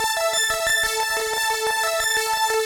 Index of /musicradar/shimmer-and-sparkle-samples/90bpm
SaS_Arp02_90-A.wav